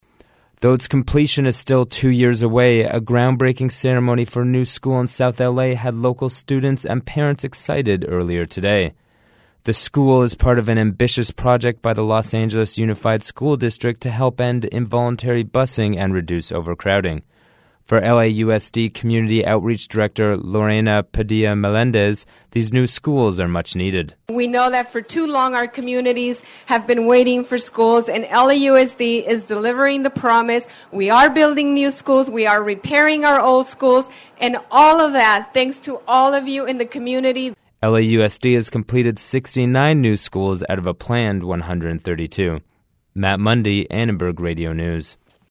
School officials, teachers and students gathered for a groundbreaking ceremony at a future school site in South Los Angeles.
schoolgroundbreaking.mp3